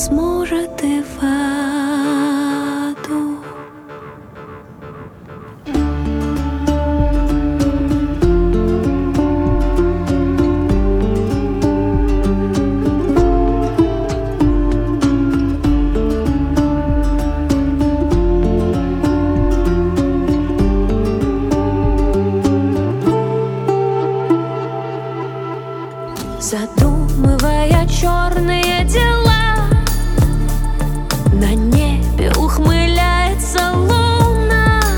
Rock Folk